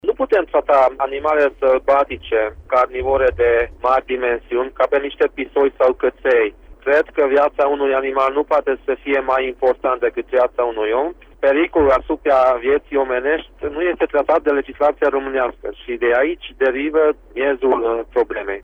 Președintele consiliului județean Harghita, Borboly Csaba: